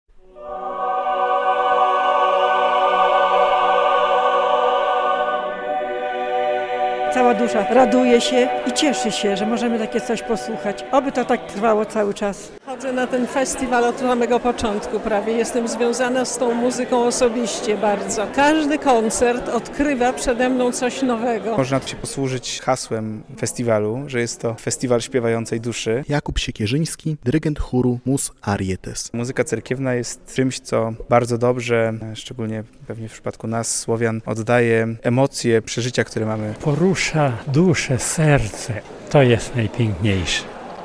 Rozpoczął się 43. Międzynarodowy Festiwal Muzyki Cerkiewnej "Hajnówka 2024" - relacja